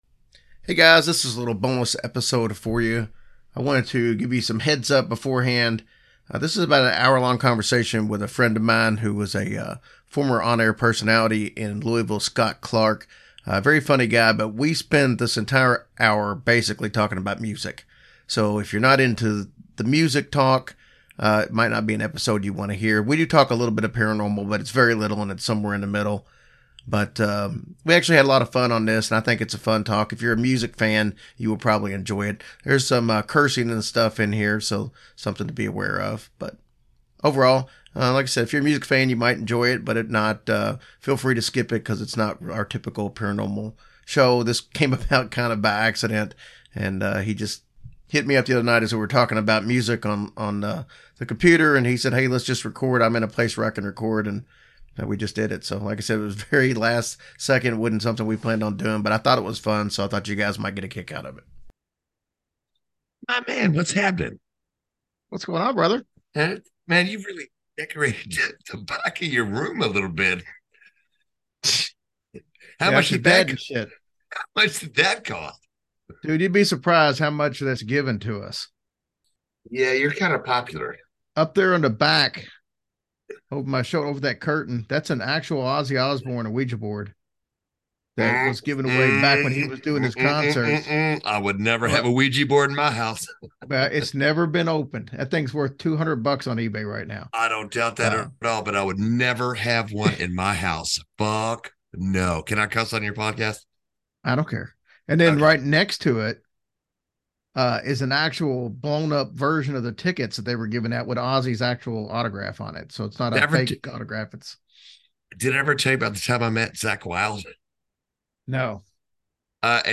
There is some cursing, so be aware.